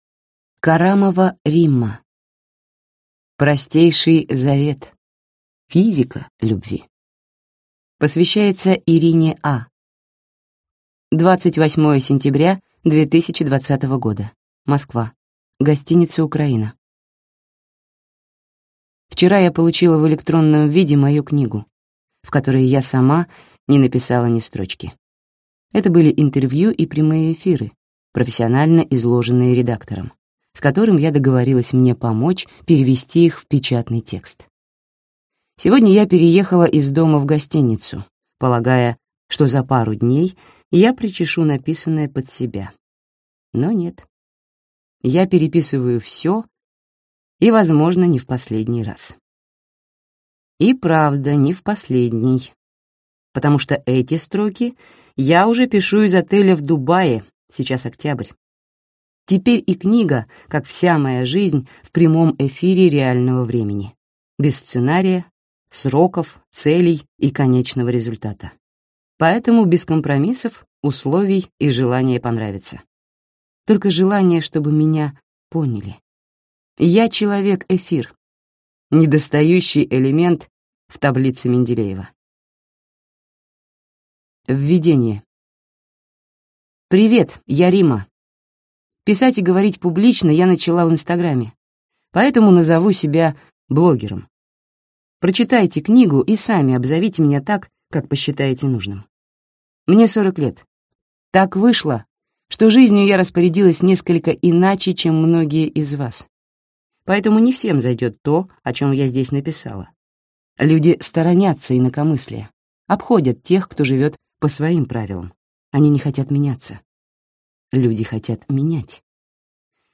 Аудиокнига Простейший Завет. Физика любви | Библиотека аудиокниг